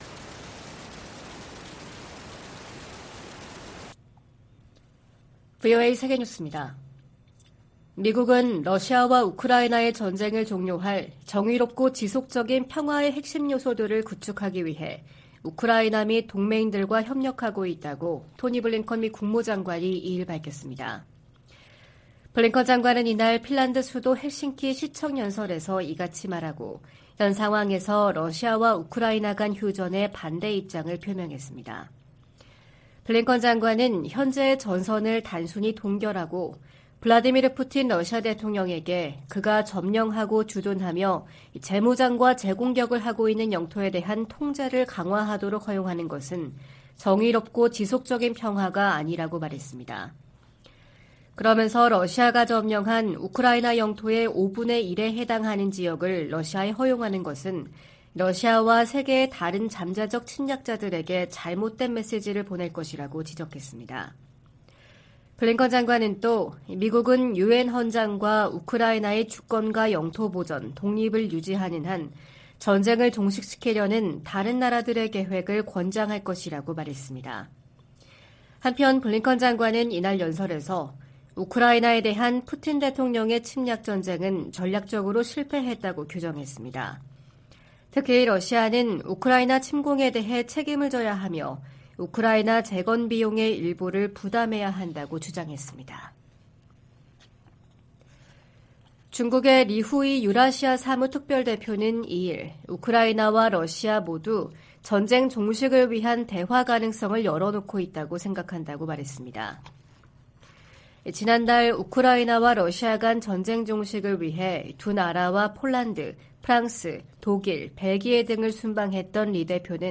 VOA 한국어 '출발 뉴스 쇼', 2023년 6월 3일 방송입니다. 유엔 안보리가 미국의 요청으로 북한의 위성 발사에 대한 대응 방안을 논의하는 공개 회의를 개최합니다. 미국과 한국 정부가 북한 해킹 조직 '김수키'의 위험성을 알리는 합동주의보를 발표했습니다.